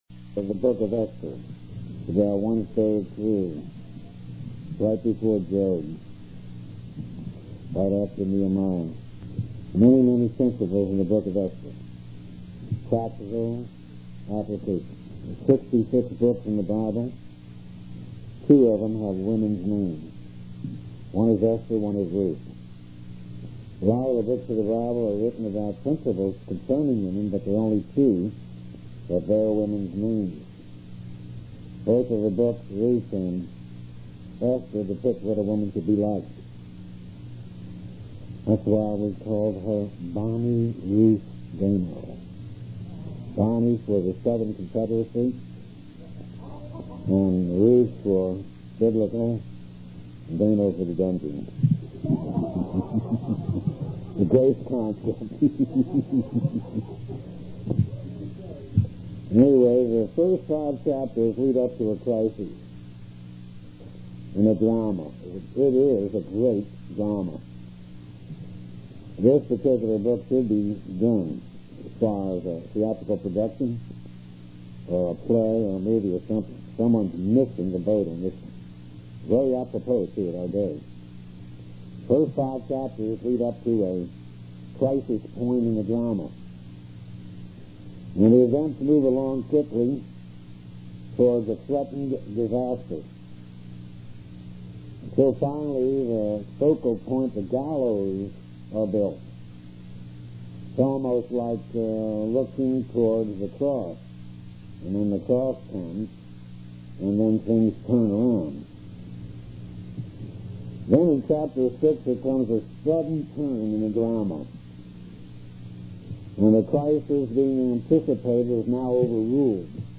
To listen to any of the Bible classes below, left click on the file name.